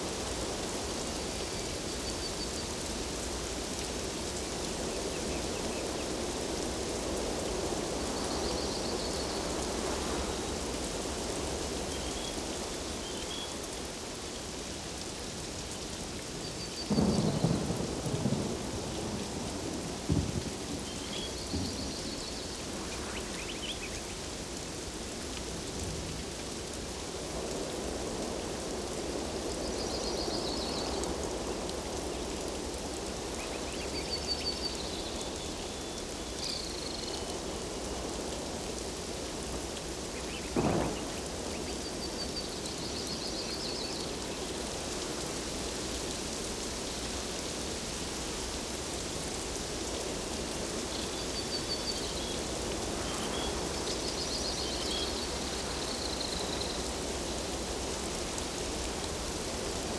Forest Day Storm.ogg